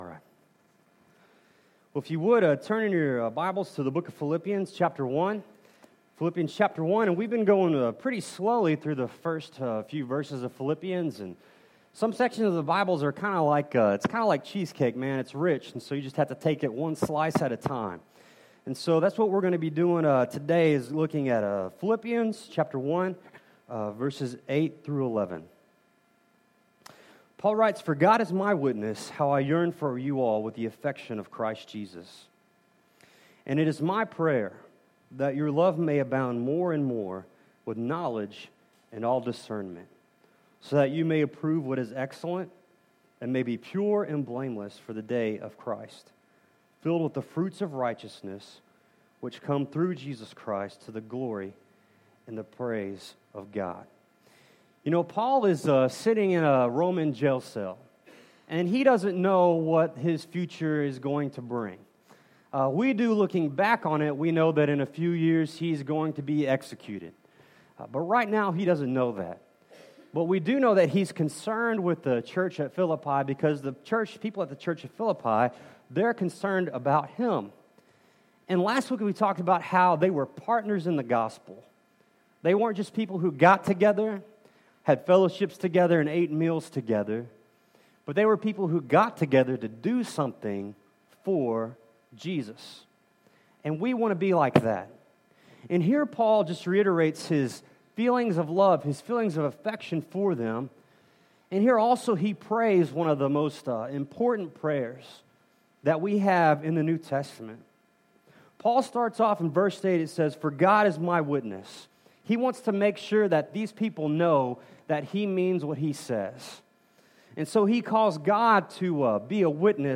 Passage: Philippians 1:8-11 Service Type: Sunday Morning